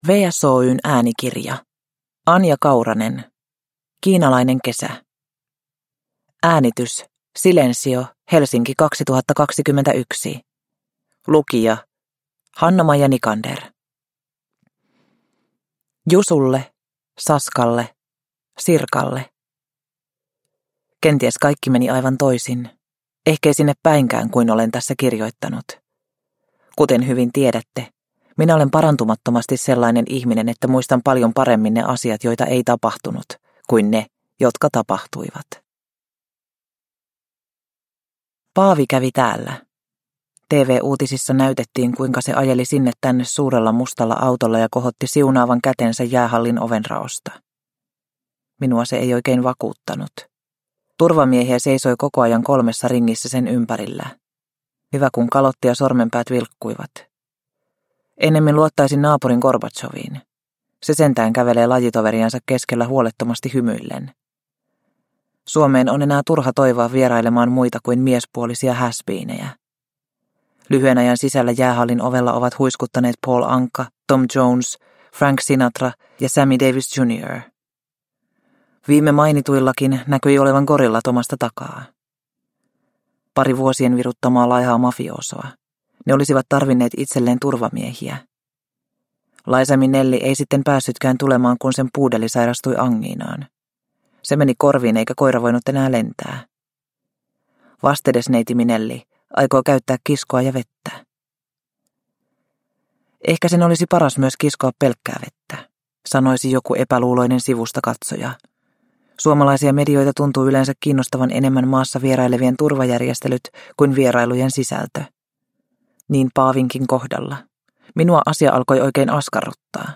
Kiinalainen kesä – Ljudbok – Laddas ner